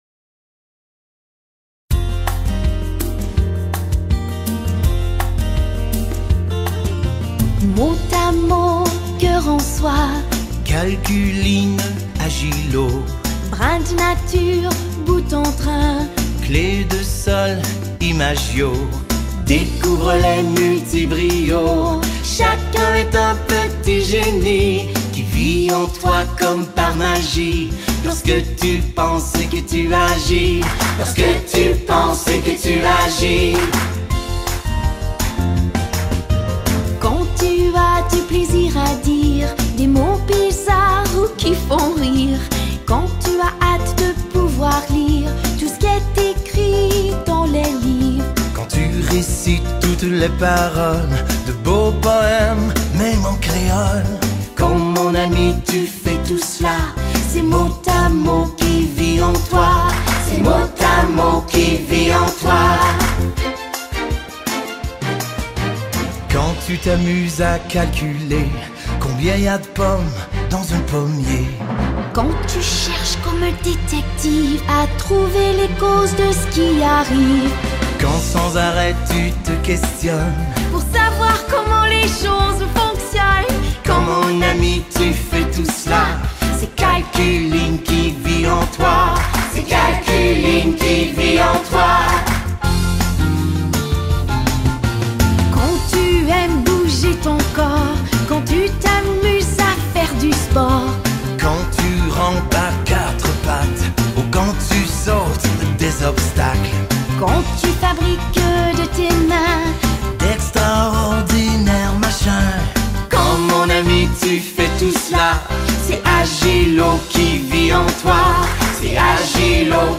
Les élèves de 2ème primaire vous présentent les « Multibrios » !